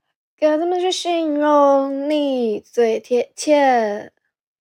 off-key.wav